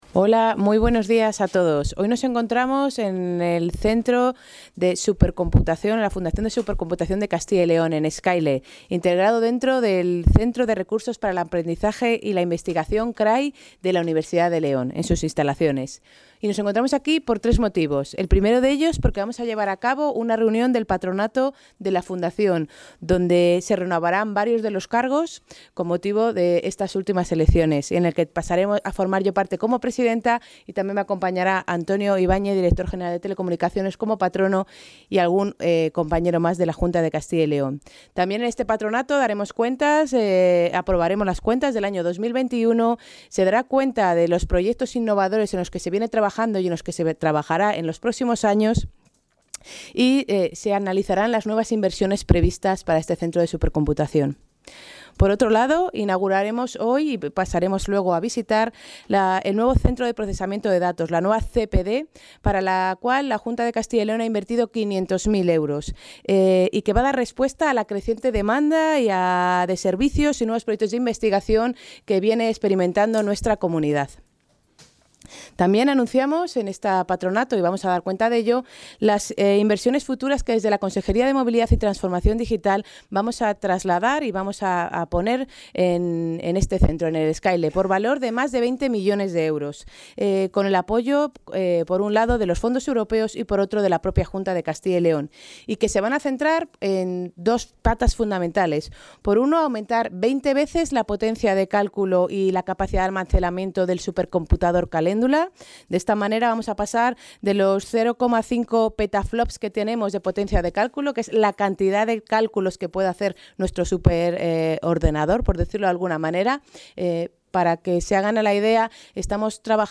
Intervención de la consejera.
El patronato de la Fundación Supercomputación Castilla y León, en la primera reunión presidida por la consejera de Movilidad y Transformación Digital, ha establecido el aumento en 20 veces de la potencia de cálculo y la capacidad de almacenamiento como principal objetivo para los próximos años. María González Corral aprovechó la visita para inaugurar el nuevo Centro de Proceso de Datos (CPD) del complejo, ubicado en el CRAI-TIC de la Universidad de León, al que se han destinado algo más de 500.000 euros y que va a dar respuesta a la creciente demanda de servicios y nuevos proyectos de investig